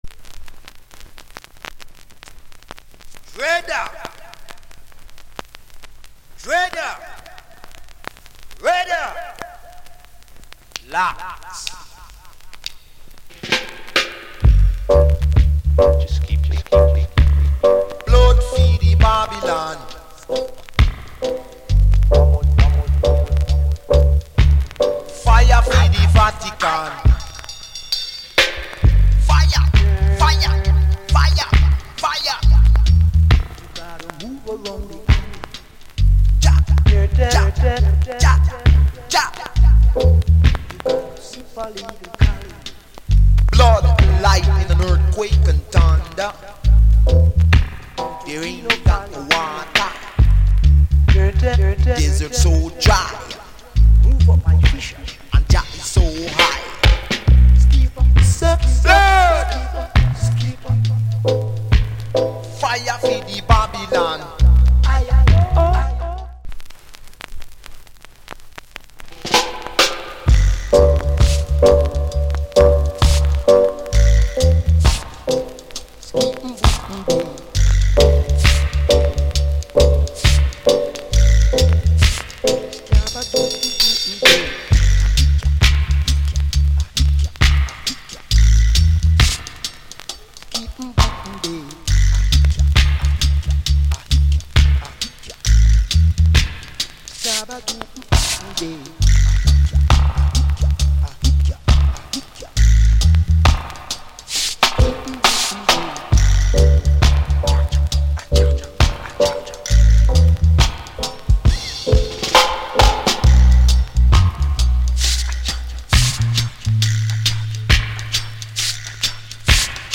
Genre Reggae70sMid / Male DJ